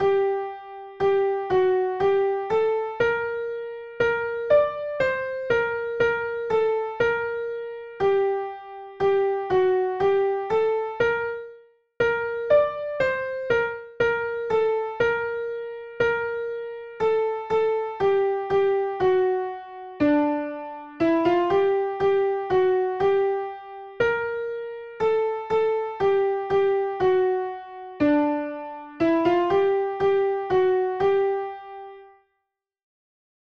Soprano Part